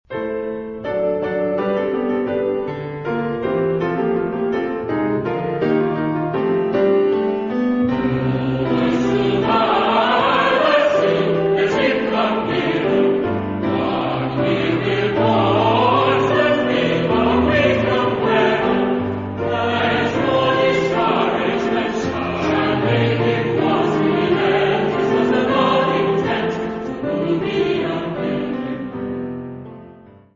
Genre-Style-Forme : Sacré ; Motet
Caractère de la pièce : majestueux
Type de choeur : SATB  (4 voix mixtes )
Instrumentation : Piano  (1 partie(s) instrumentale(s))
Tonalité : do majeur